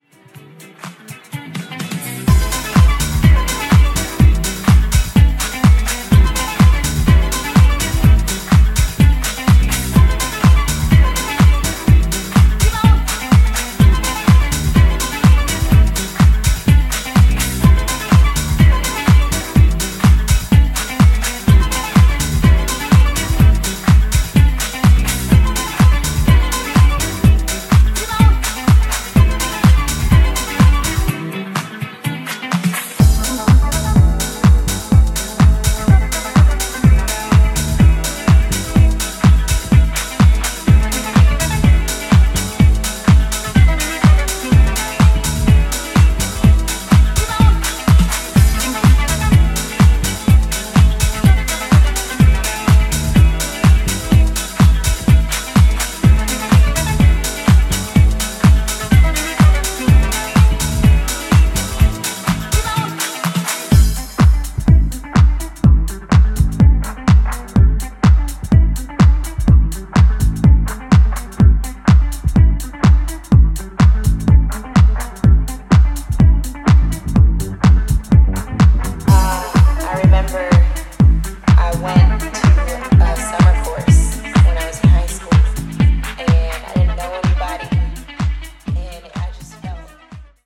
全4曲リズミカルで爽やかなディスコ＆ディープなハウスサウンドに仕上がった大スイセンの1枚です！！
ジャンル(スタイル) DISCO HOUSE